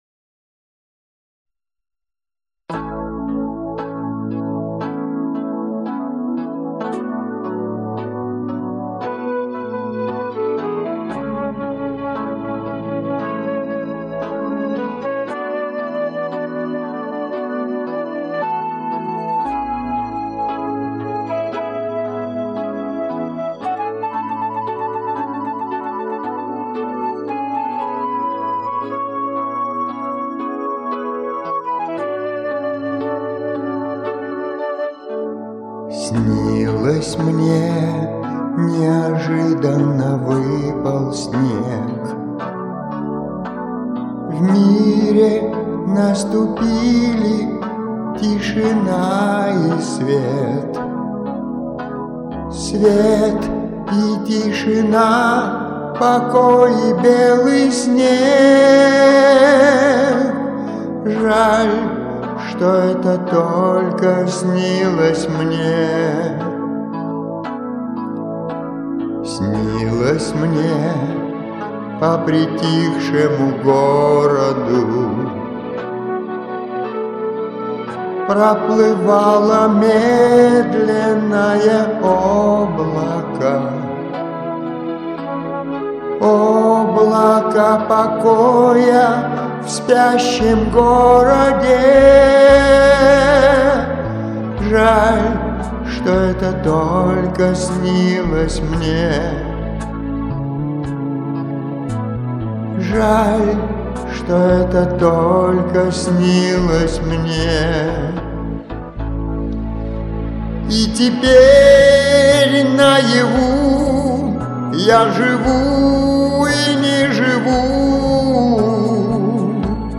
ЕСЛИ ЧЕСТНО ЗАПИСЬ ОЧЕНЬ СТАРАЯ...